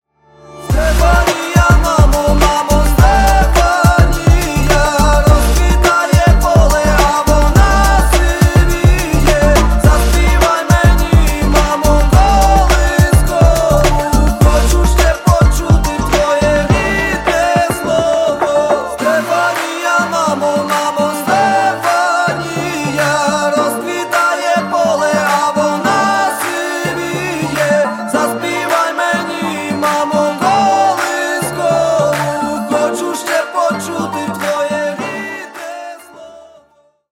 • Качество: 128, Stereo
душевные
фолк